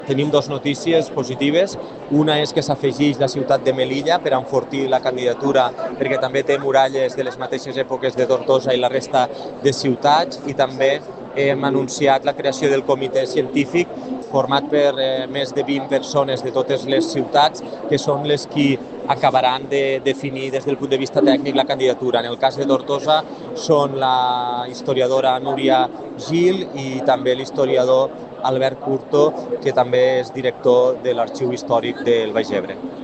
La trobada s’ha fet en el marc de la Fira Internacional de Turisme (FITUR) a Madrid, a l’estand de les Illes Balears. També s’han anunciat els membres del comitè científic de la candidatura, que estarà integrat per experts en patrimoni de cadascuna de les ciutats.
Així ho ha anunciat l’alcalde de Tortosa, Jordi Jordan…